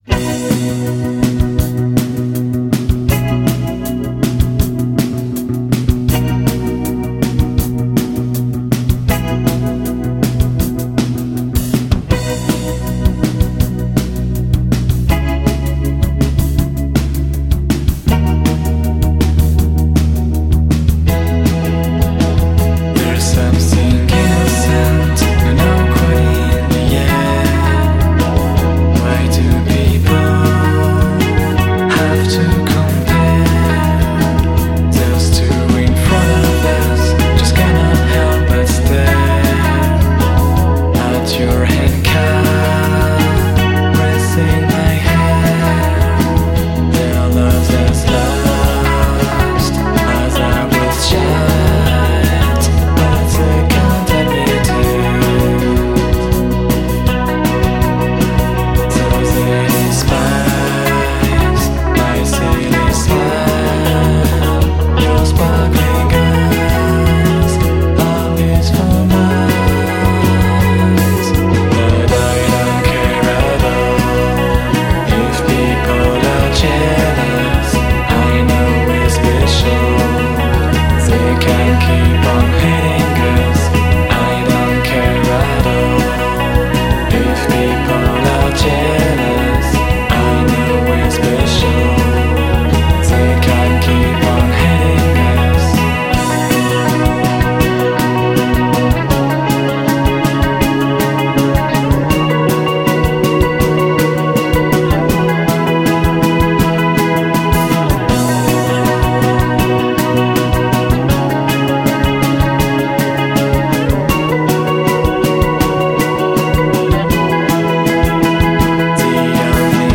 Il più classico suono indiepop può apparire docile e curare.